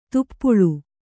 ♪ tuppuḷu